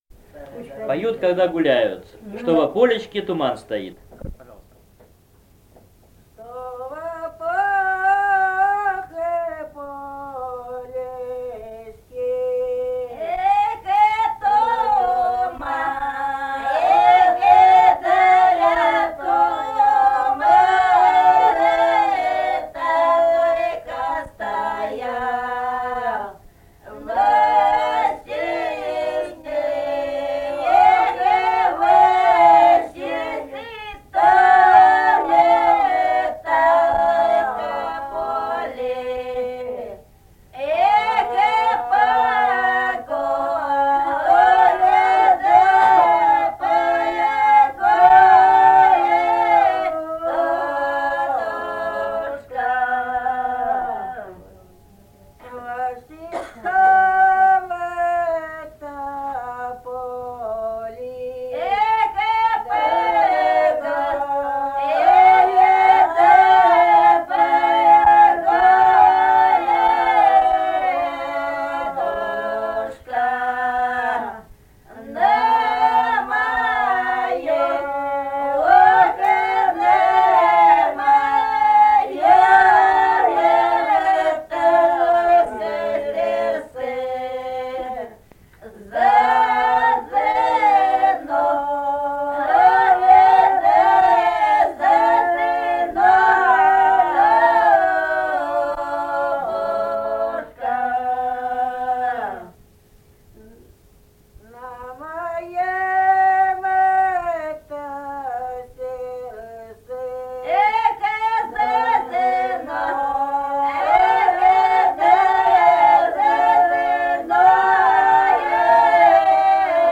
Песни Убинско-Ульбинской долины Что во полечке туман только стоял (поют на гуляниях), с. Бутаково.